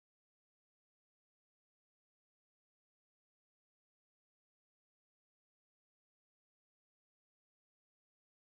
Рингтон без звука - для родных, близких и любииимых
silence.mp3